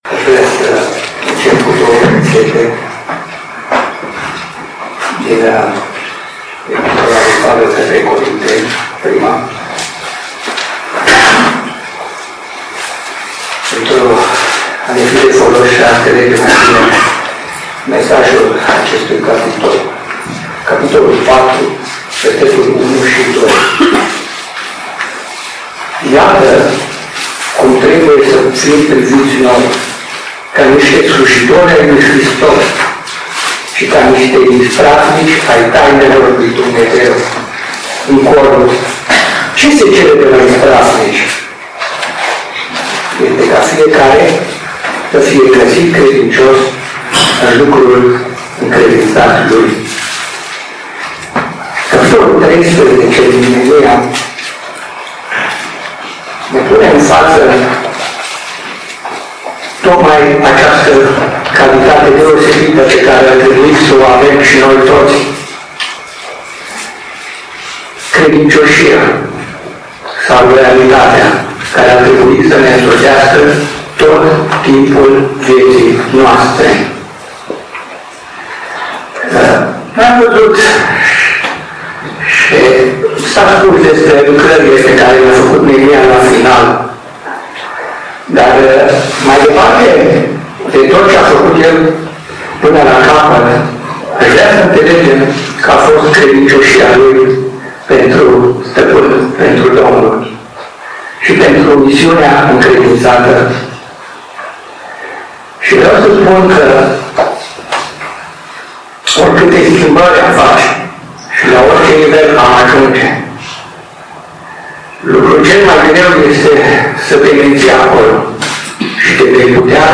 Tineret, seara Predică, Faptele apostolilor 15:22-35